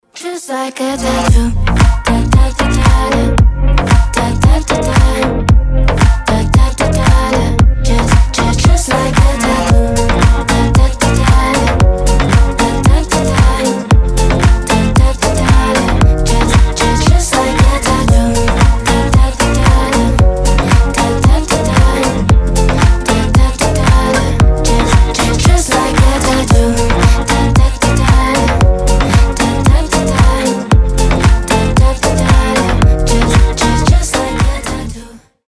• Качество: 320, Stereo
Dance Pop
чувственные
красивый женский голос